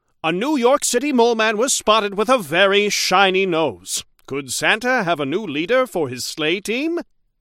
Newscaster voice line - A New York City mole man was spotted with a very shiny nose.
Newscaster_seasonal_krill_unlock.mp3